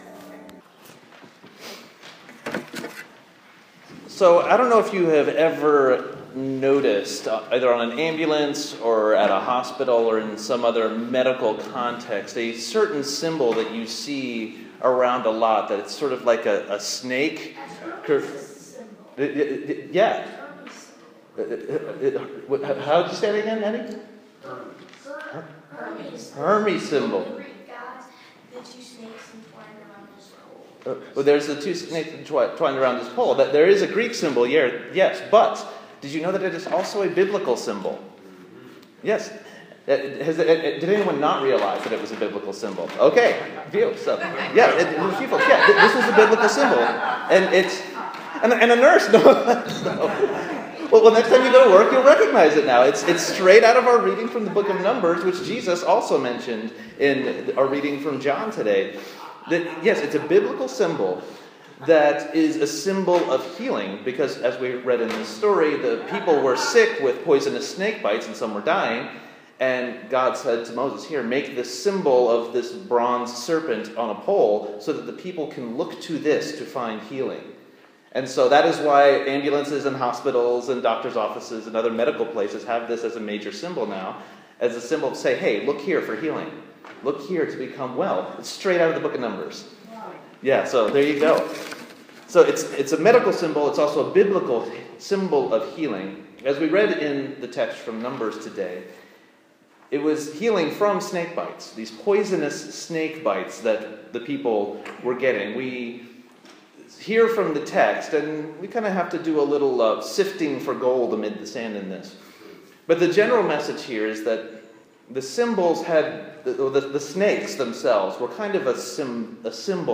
Sermon for the fourth Sunday in Lent
sermon-lent-4-b-2015.m4a